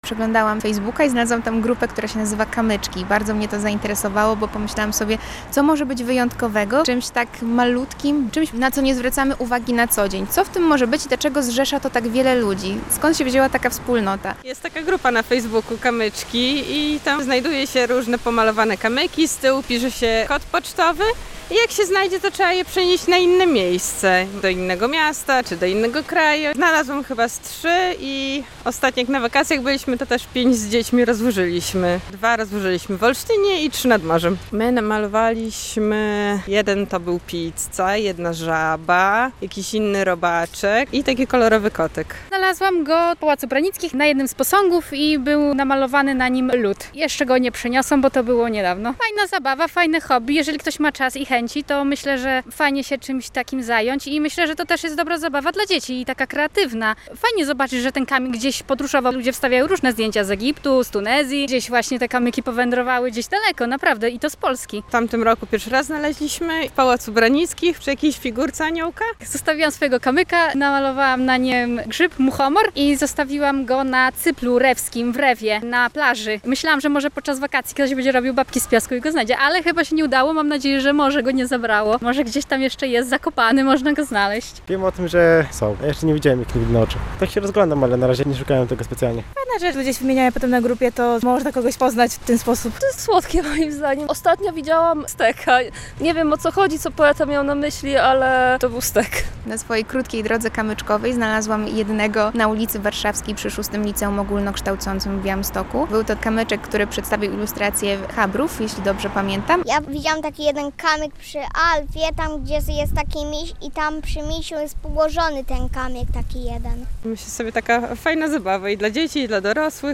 relacja
Białostoczanie z entuzjazmem opowiadają, jak odkryli tę inicjatywę, która nie tylko promuje sztukę, ale także niesie pozytywne emocje do kolejnych osób.